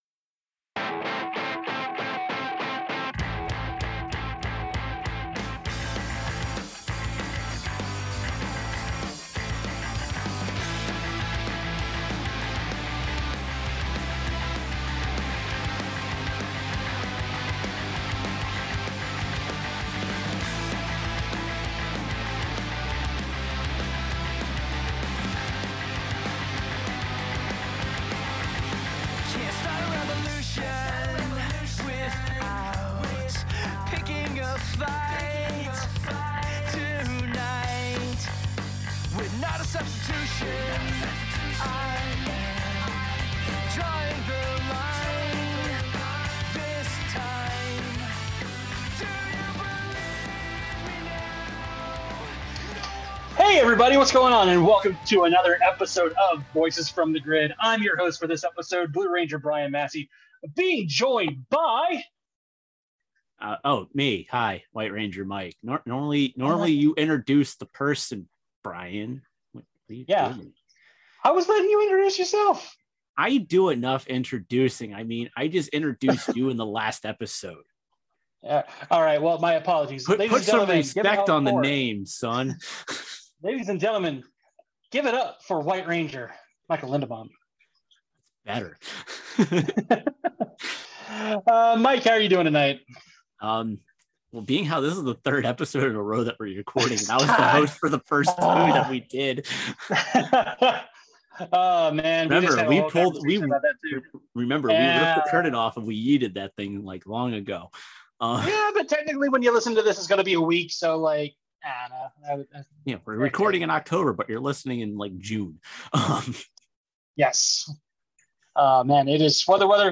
Opening Music